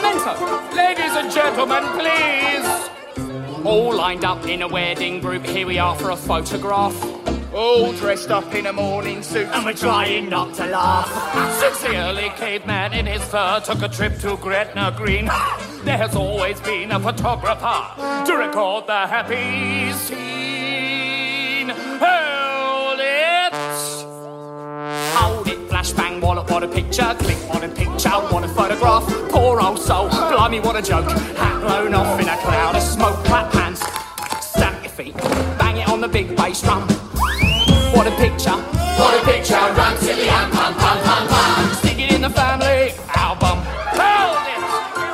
Medium voices